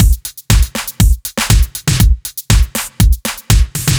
Index of /musicradar/french-house-chillout-samples/120bpm/Beats